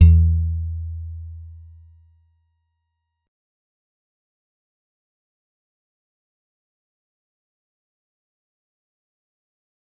G_Musicbox-E2-mf.wav